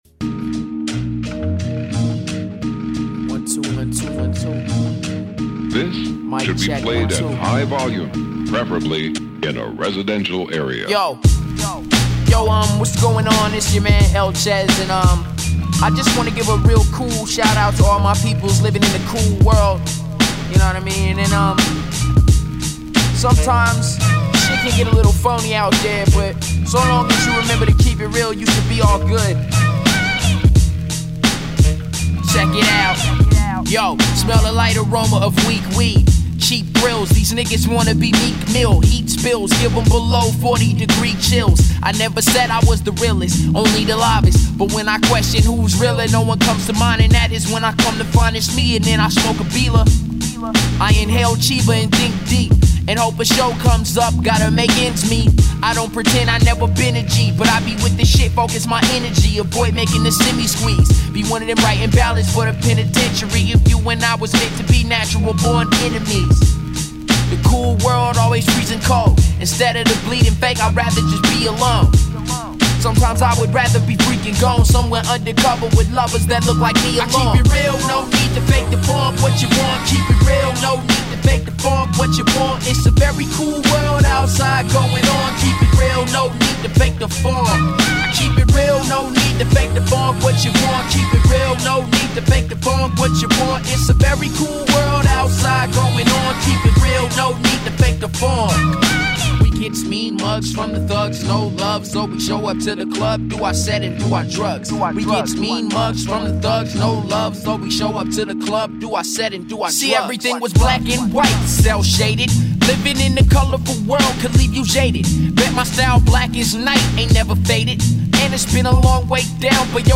independent hip-hop